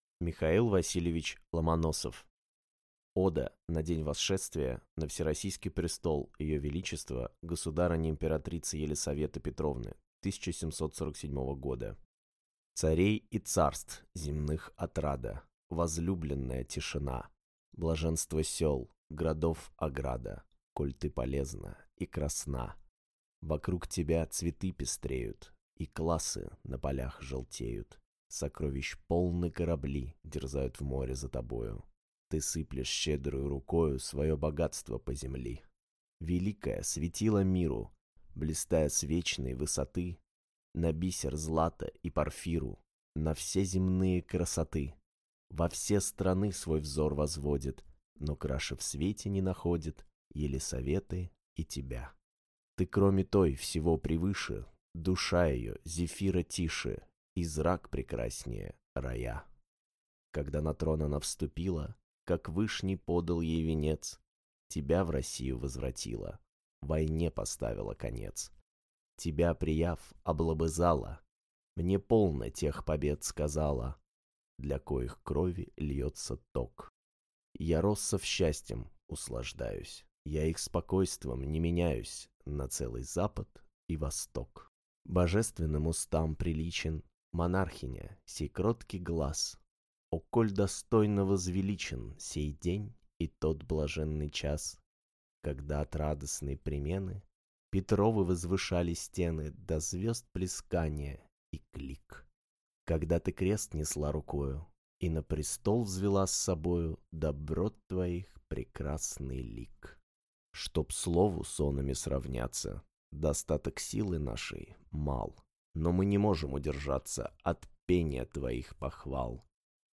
Аудиокнига Ода на день восшествия на всероссийский престол ее величества государыни императрицы Елисаветы Петровны 1747 года | Библиотека аудиокниг